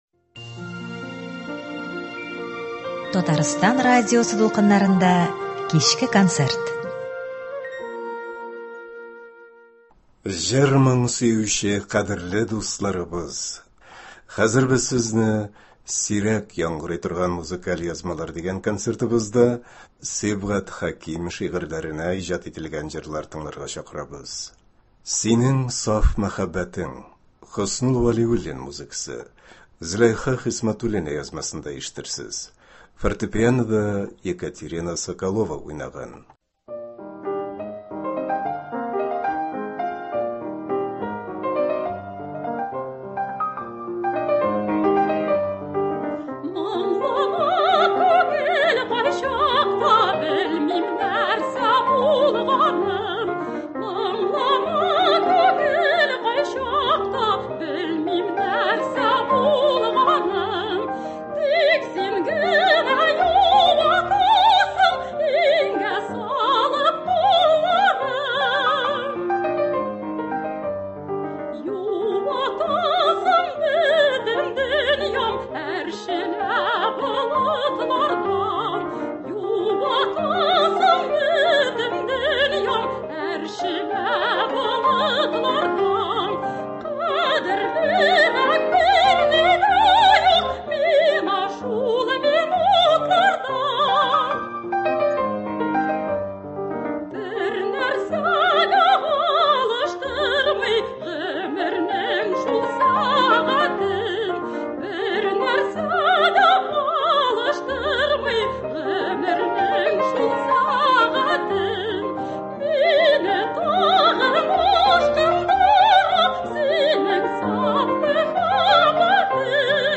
Сирәк яңгырый торган музыкаль язмалар.